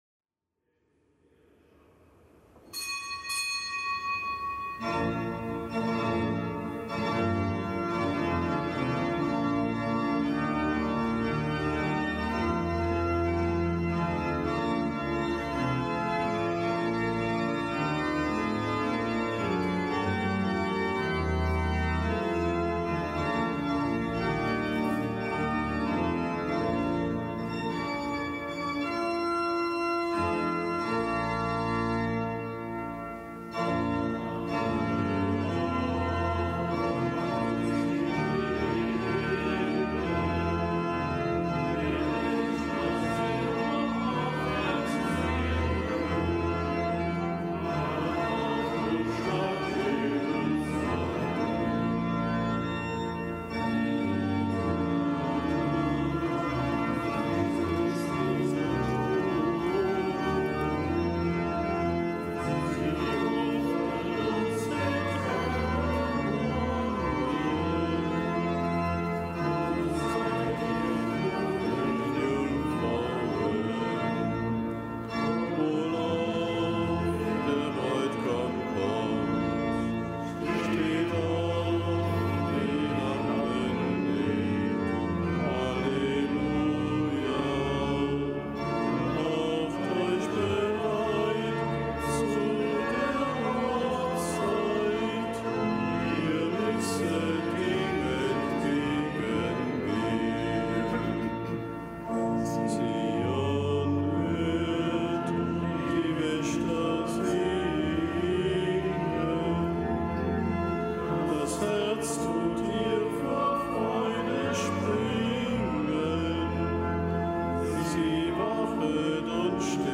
Kapitelsmesse aus dem Kölner Dom am Dienstag der zweiten Adventswoche. Nichtgebotener Gedenktag Unserer Lieben Frau von Loreto.